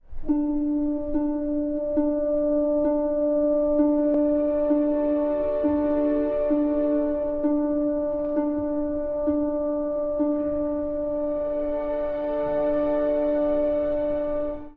古い音源なので聴きづらいかもしれません！（以下同様）
曲は、ハープによる12回の音から始まります。
これは真夜中12時の鐘の音を表しており、「墓場の真夜中」が幕を開ける瞬間です。